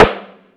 Snare 05.wav